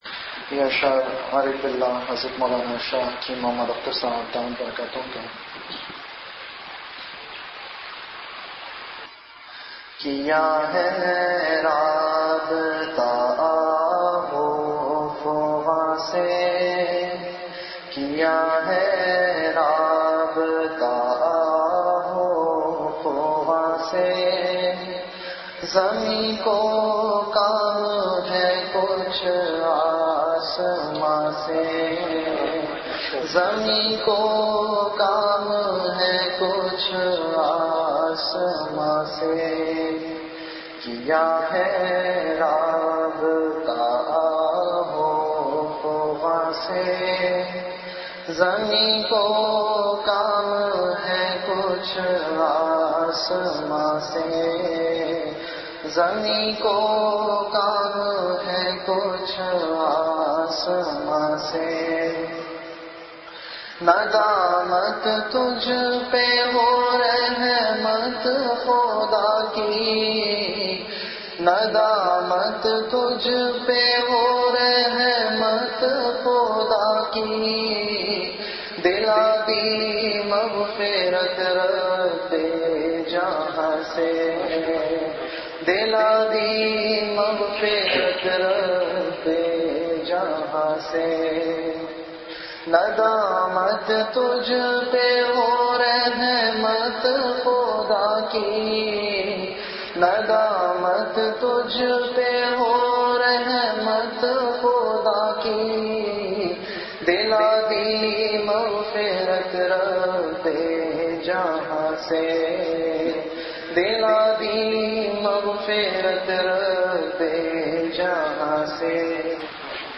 An Islamic audio bayan
Delivered at Home.